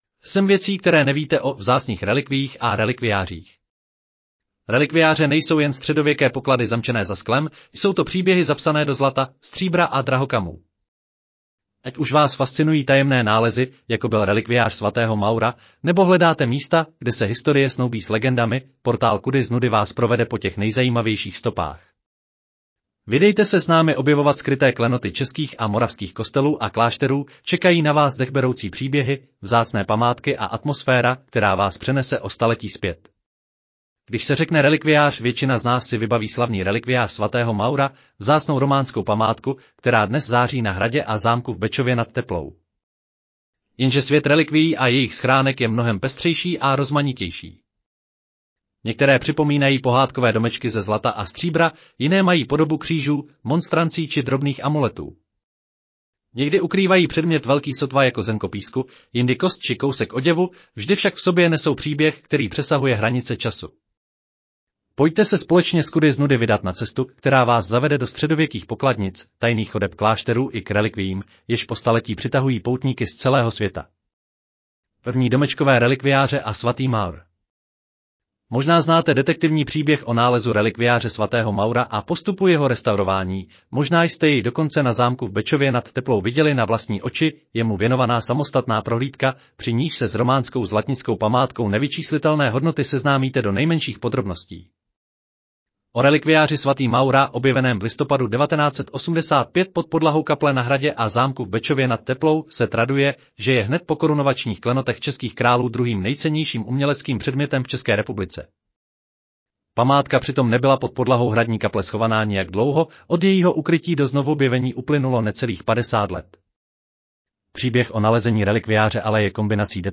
Audio verze článku 7 věcí, které nevíte o... vzácných relikviích a relikviářích